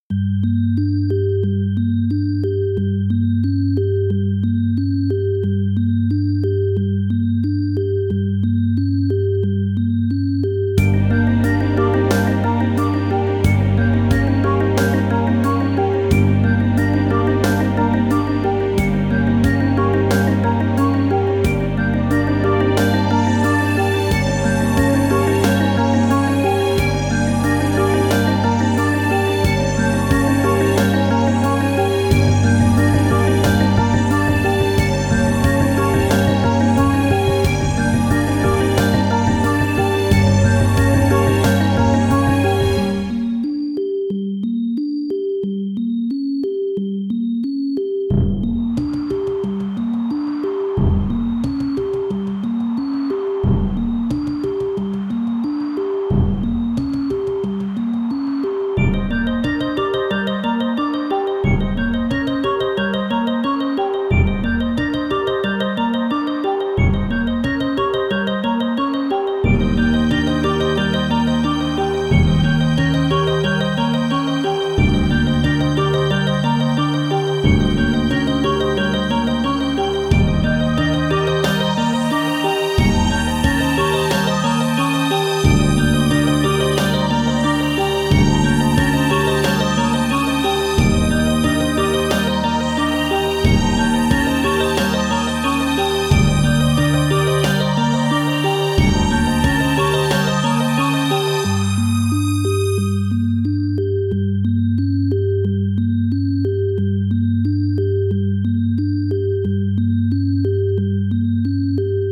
不安 怪しい